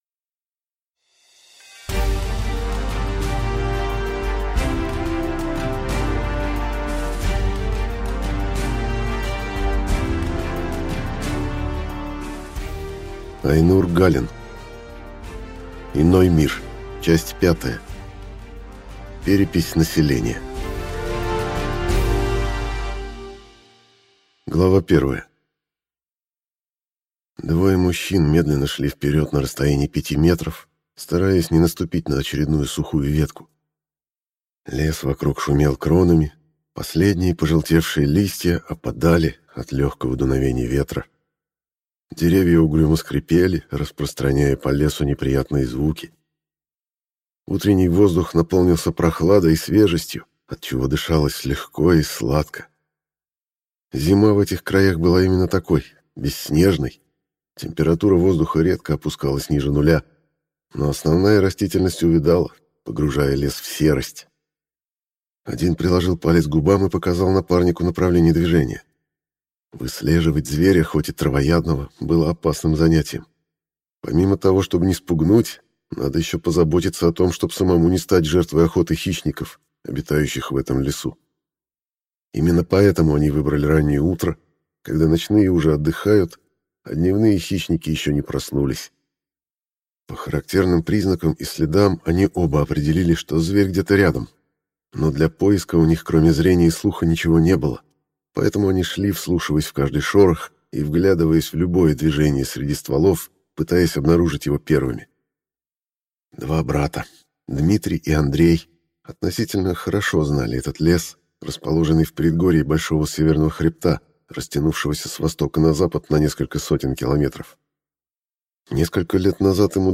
Аудиокнига Иной мир. Морпехи. Книга пятая. Перепись населения | Библиотека аудиокниг